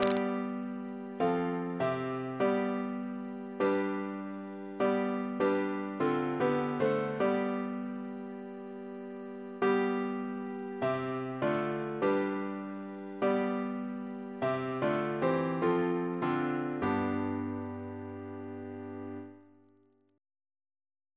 Double chant in G Composer